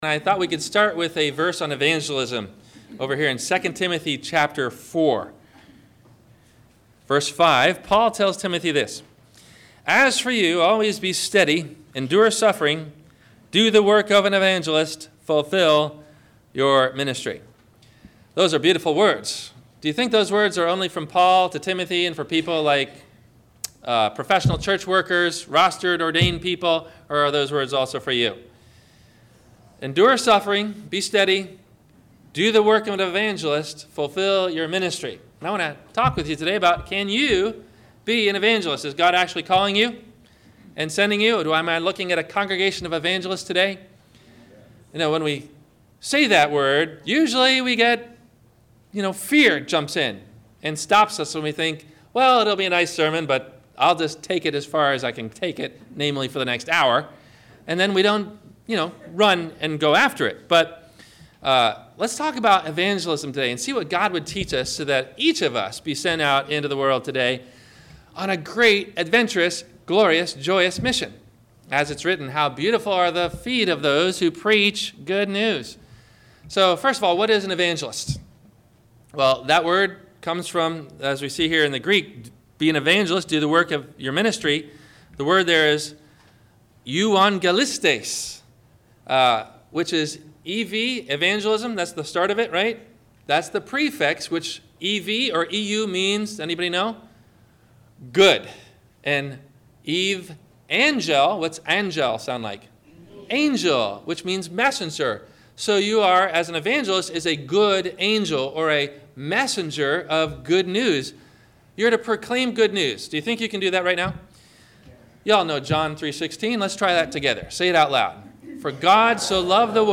Are You a Link in the Chain of Someone's Salvation (Evangelism) ? - Sermon - September 25 2016 - Christ Lutheran Cape Canaveral